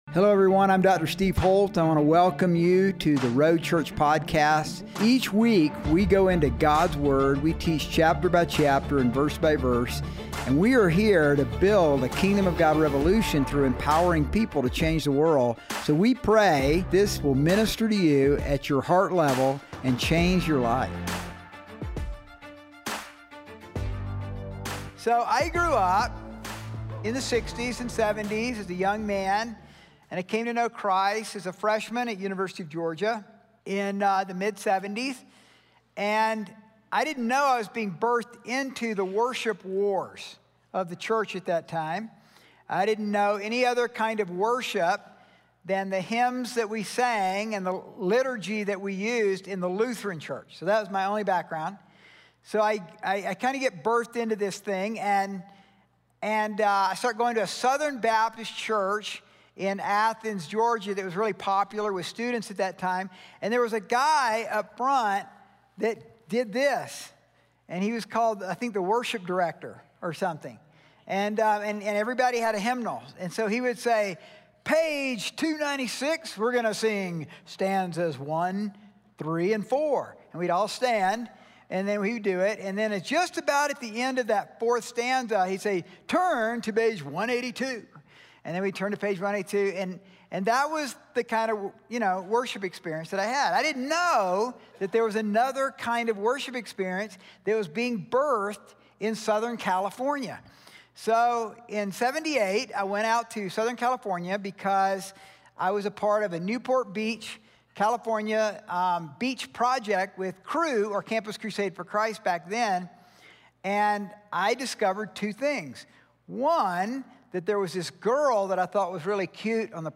Sermons | The Road Church